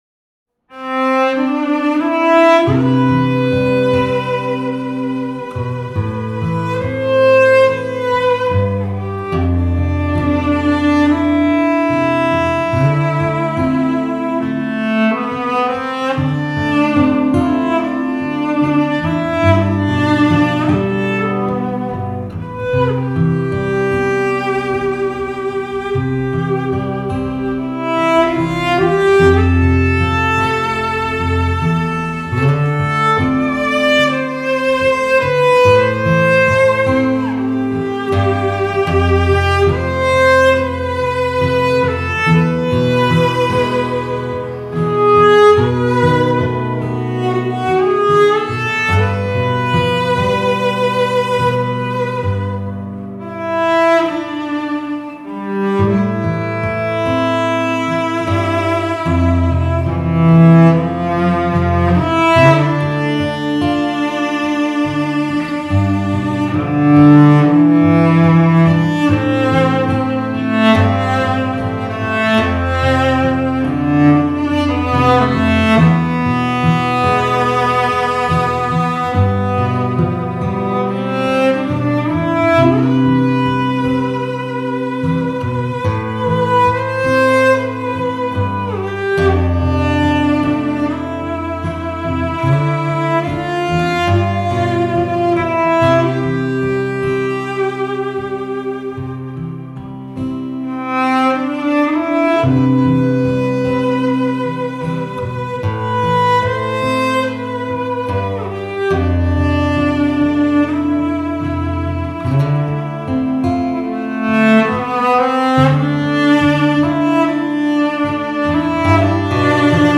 Cello
Guitar
little instrumental